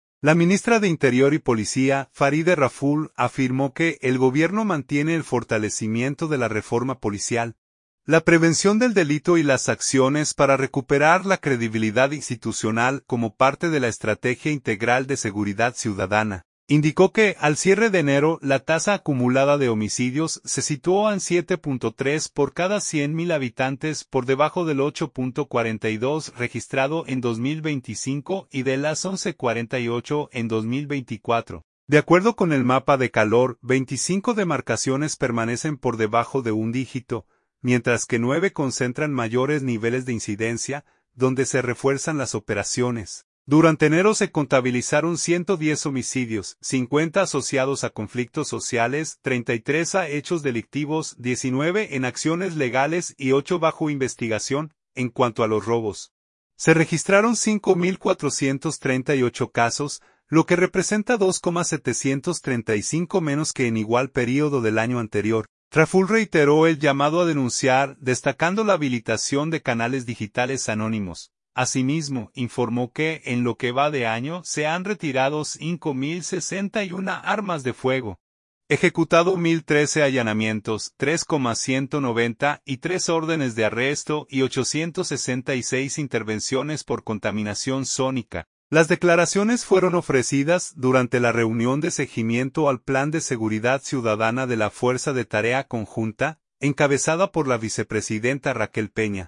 Las declaraciones fueron ofrecidas durante la reunión de seguimiento al Plan de Seguridad Ciudadana de la Fuerza de Tarea Conjunta, encabezada por la vicepresidenta Raquel Peña.